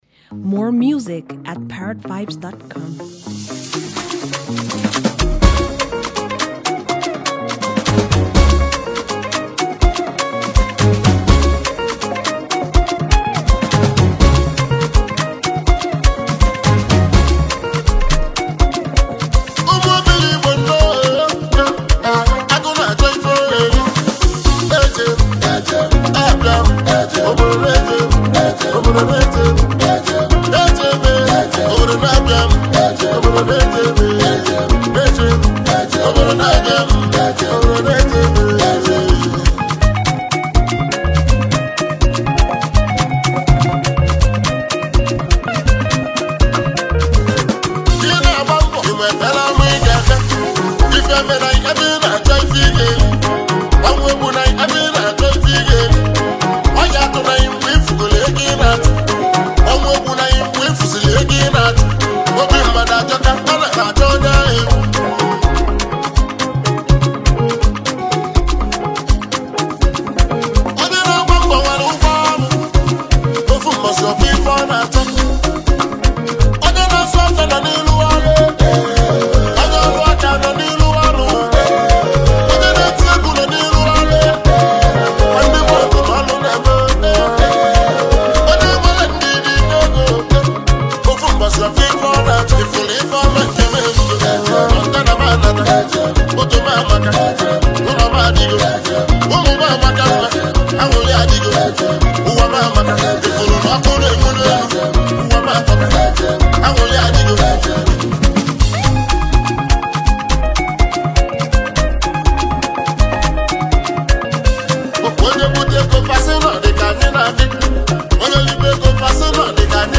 Incredibly talented Nigerian music duo
electrifying single hit track